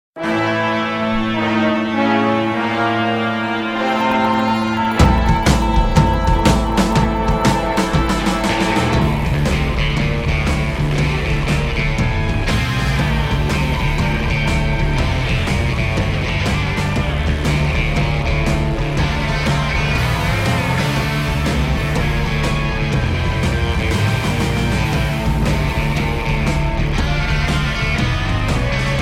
• Качество: 128, Stereo
инструментальные
Hard rock
саундтрек